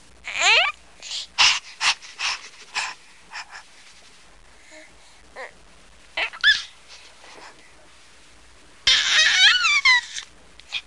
Baby Goo Sound Effect
Download a high-quality baby goo sound effect.
baby-goo.mp3